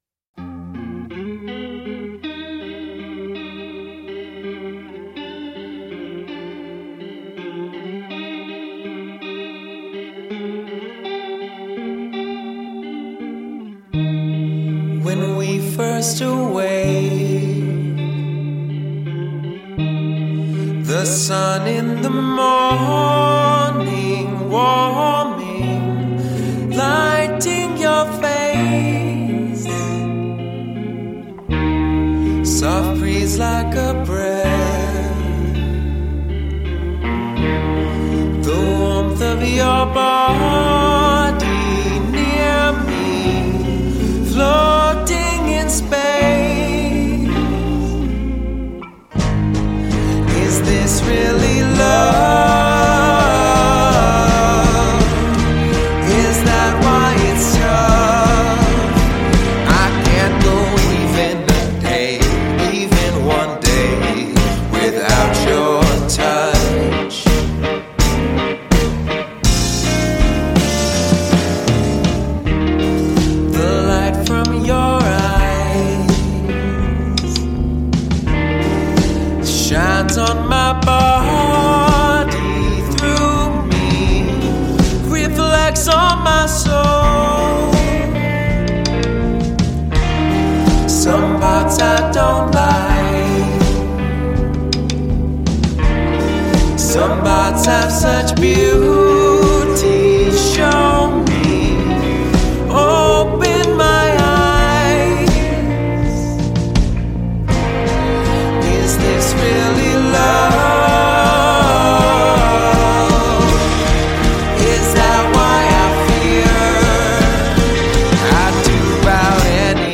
who make eclectic pop music.